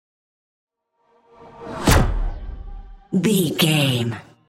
Pass by fast speed flash
Sound Effects
Fast
futuristic
intense
sci fi
vehicle